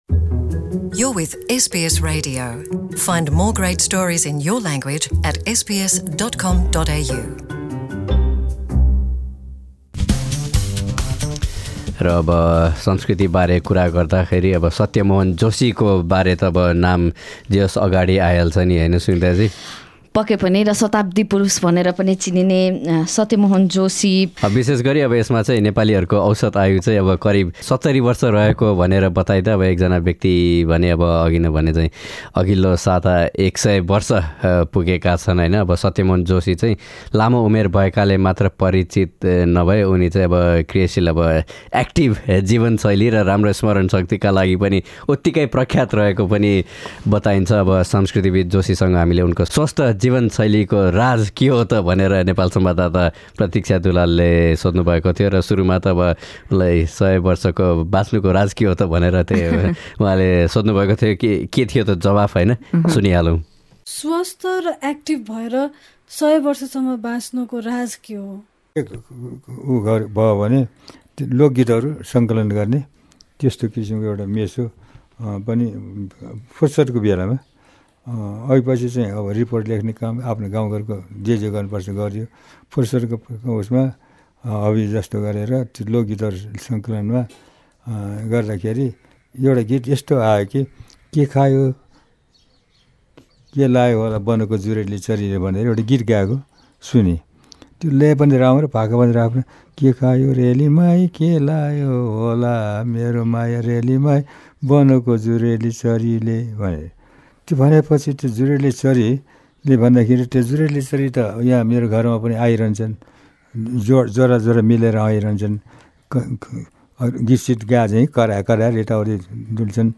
Nepal's cultural icon Satya Mohan Joshi spoke to SBS Nepali about leading an anxiety free life.